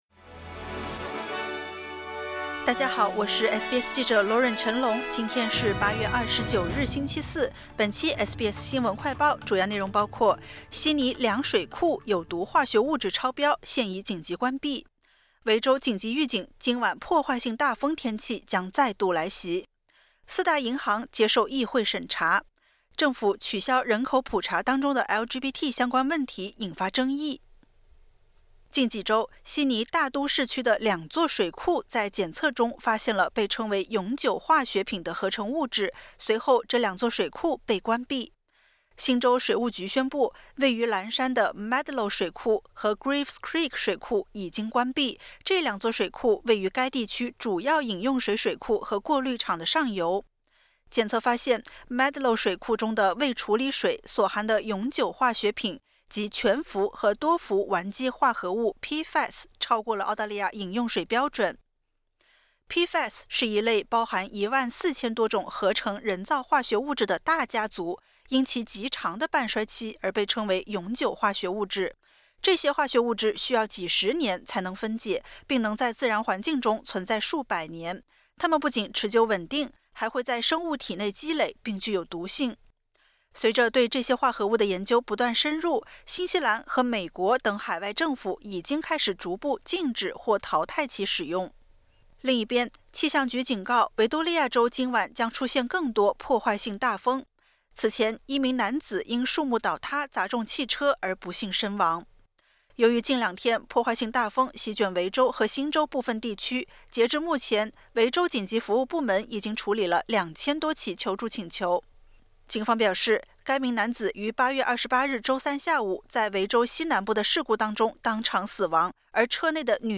【SBS新闻快报】悉尼两水库有毒化学物质超标 已紧急关闭